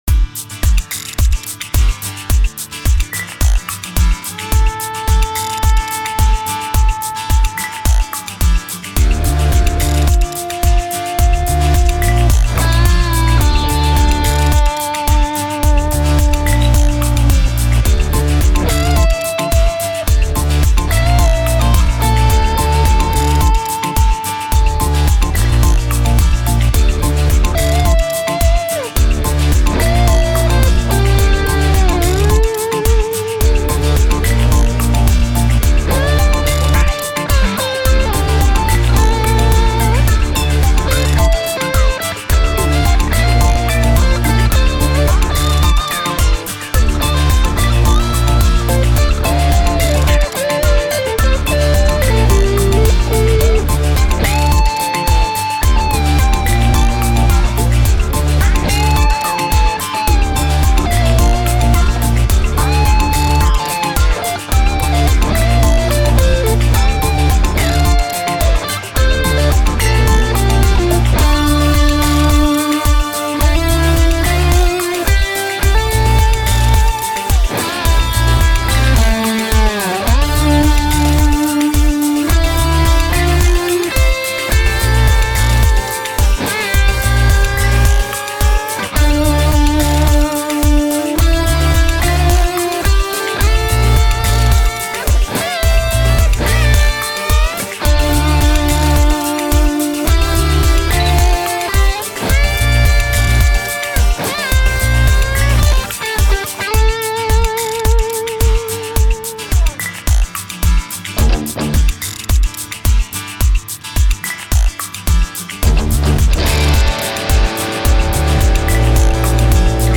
Das habe ich dann auch gleich genutzt, es sind keine 125, sondern nur ca. 8 Spuren, aber das hat auch Spaß gemacht :-) Zu hören sind Strat, Tele und Luk Skywalker, aufgenommen über meinen Brunetti mit diversen Pedalen, die hier so rumliegen (Okko Diablo und Coca Comp, MEK Zentaurus, Kasleder Boor, Voodoo Lab Proctavia, Servus Yodelmaster).